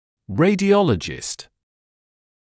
[ˌreɪdɪ’ɔləʤɪst][ˌрэйди’олэджист]рентгенолог; радиолог